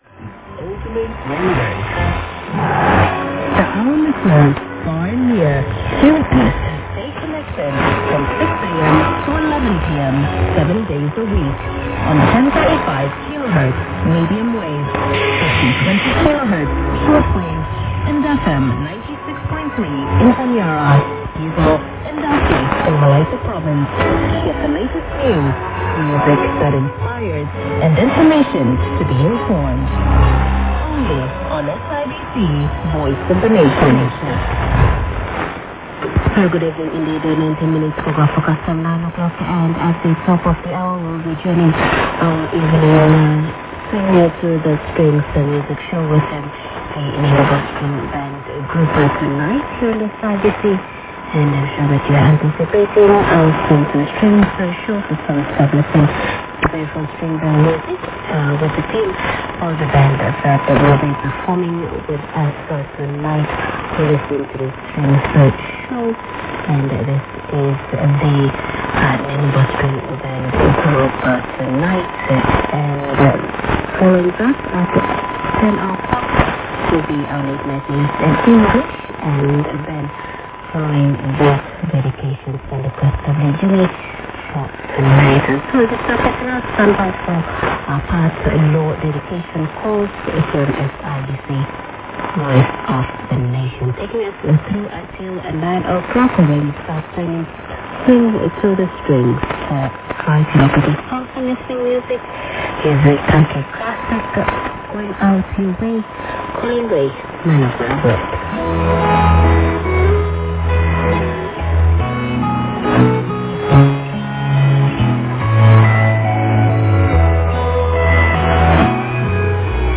<受信地：東京都江東区新砂 東京湾荒川河口 RX:ICF-SW7600GR ANT:AN-12>
※00:07-00:21 女性「～ day connection from 6 am to 11 pm 7 days of week on 10-35 kHz Medium Wave 50-20 kHz Short Wave and FM 96.3 in Honiara」
※01:31-01:34 女性アナ「SIBC Voice of the Nation」